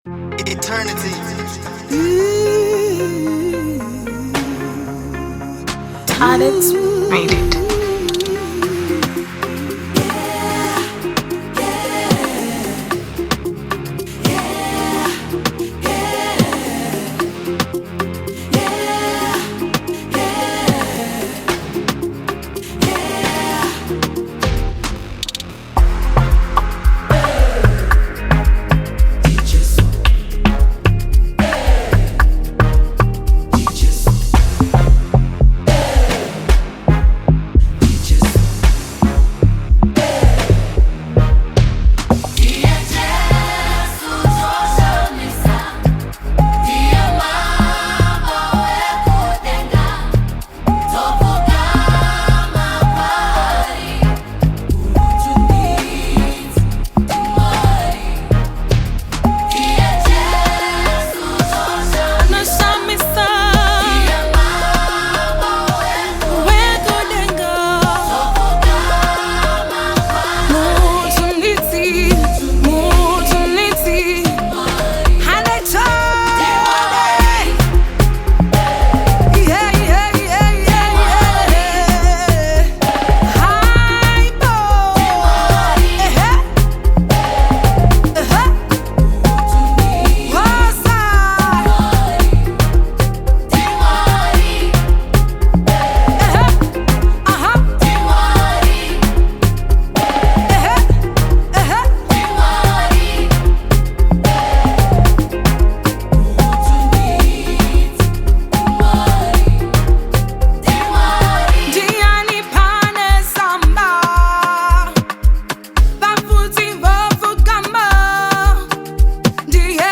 smooth vocals
Afrobeat
With its upbeat tempo and catchy sounds